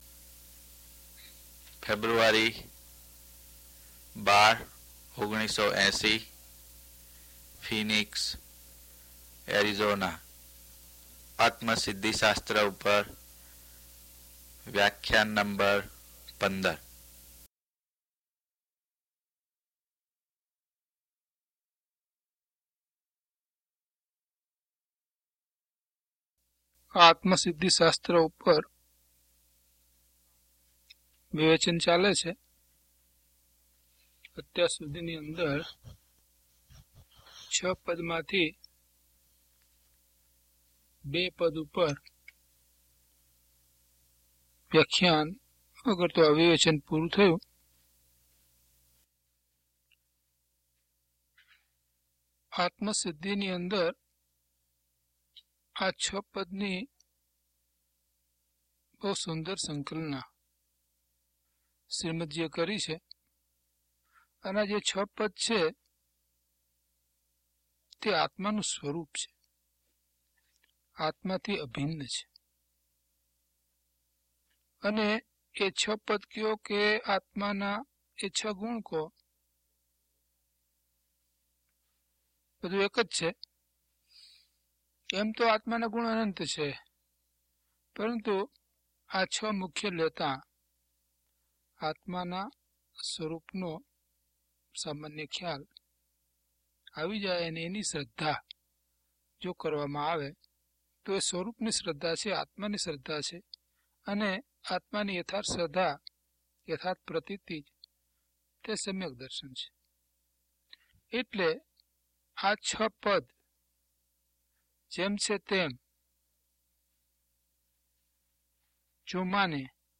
DHP024 Atmasiddhi Vivechan 15 - Pravachan.mp3